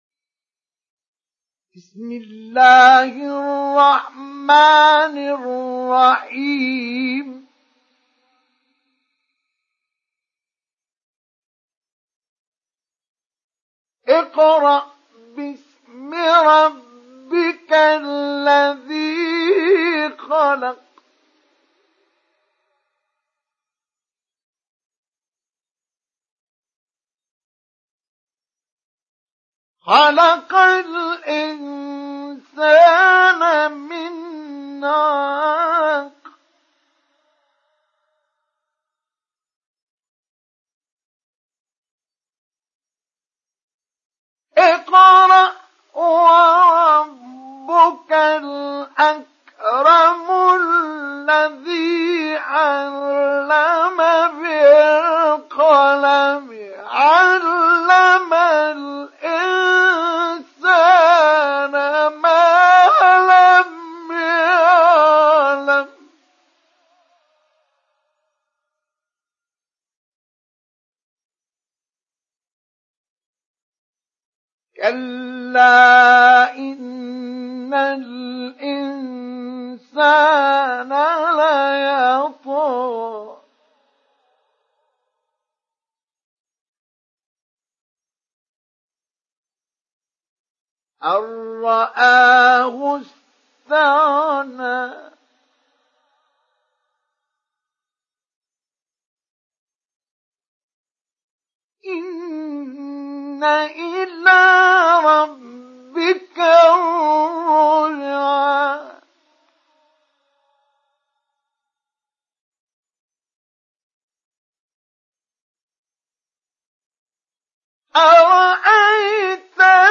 Surat Al Alaq Download mp3 Mustafa Ismail Mujawwad Riwayat Hafs dari Asim, Download Quran dan mendengarkan mp3 tautan langsung penuh
Download Surat Al Alaq Mustafa Ismail Mujawwad